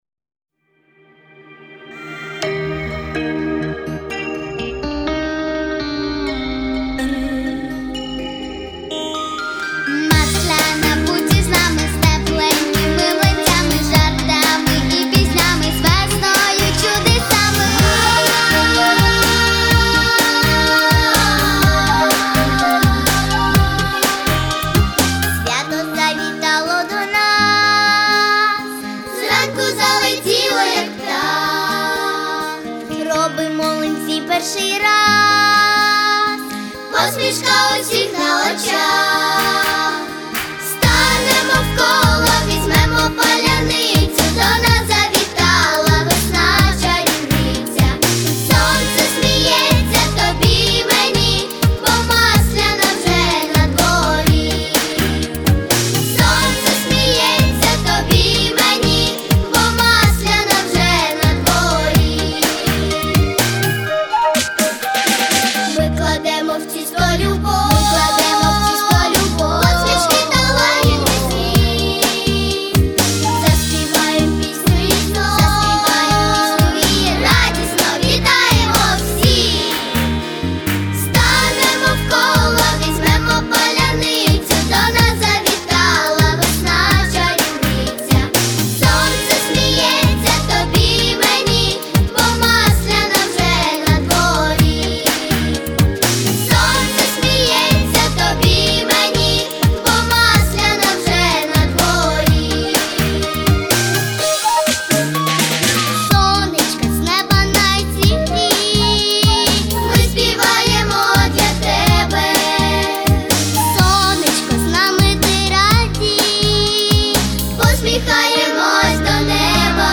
Весёлая детская песня
для младшего детского ансамбля. Тональность ми-бемоль мажор.